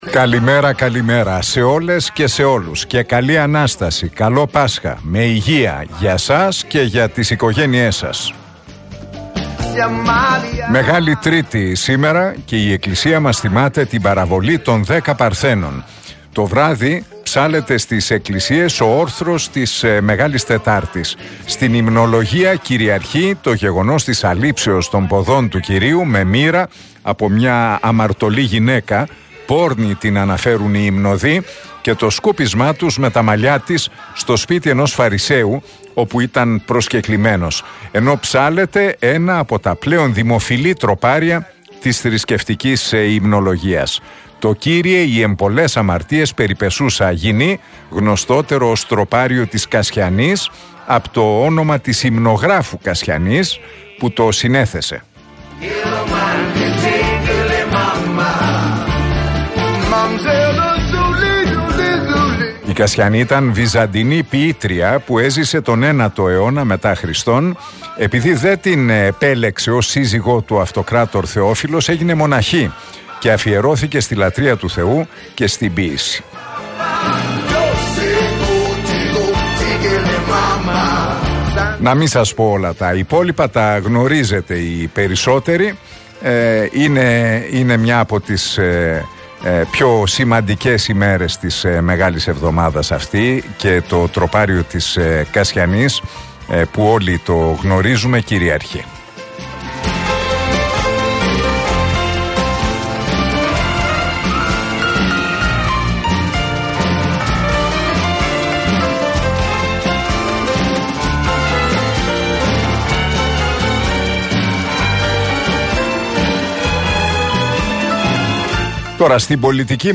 Ακούστε το σχόλιο του Νίκου Χατζηνικολάου στον RealFm 97,8, την Μεγάλη Τρίτη 11 Απριλίου 2023.